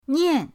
nian4.mp3